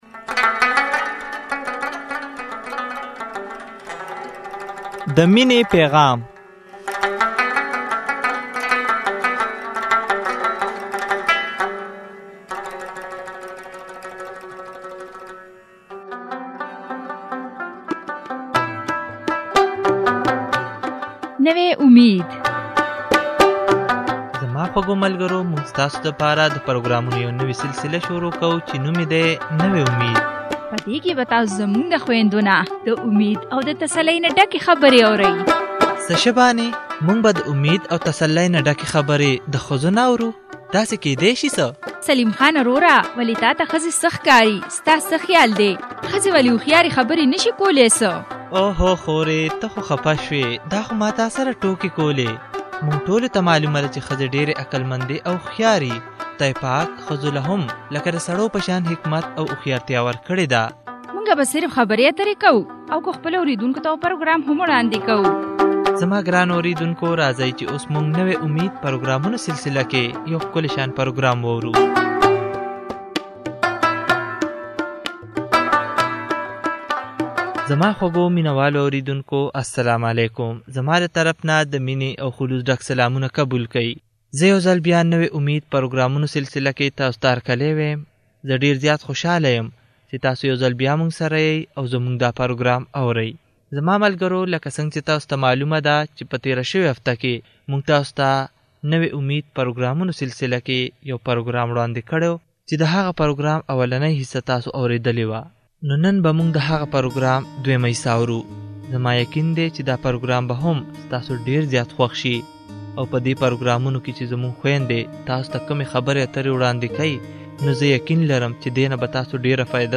په دې پروګرام کښې درې ښځې د کارونو په حقله خبرې کوى چې کارونه کله کله ډېر بوجه جوړ شى او انسان ستړې کوى.